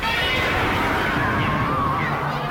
File:Troidon roar.ogg
Troidon_roar.ogg